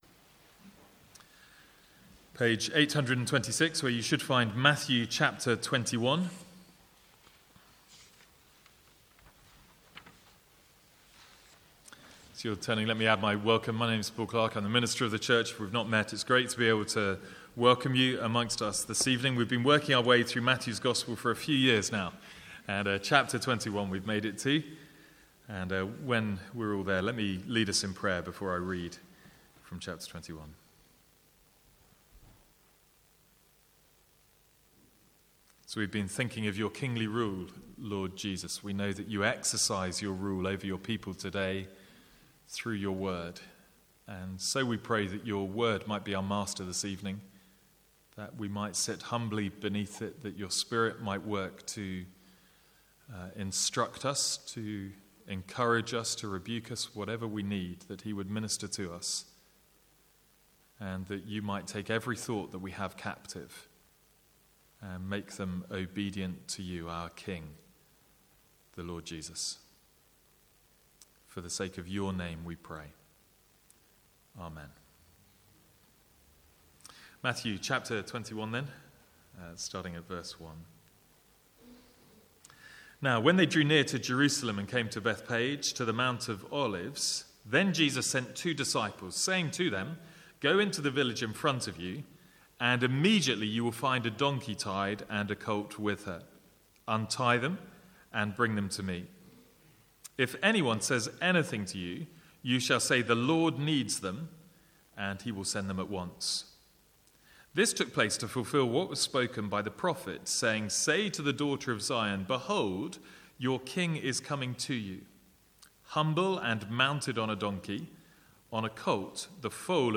From the Sunday evening series in Matthew.